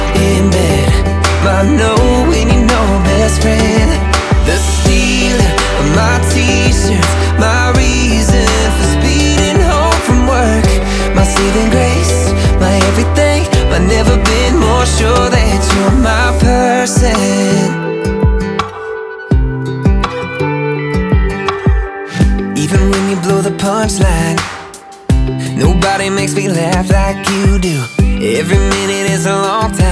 • Country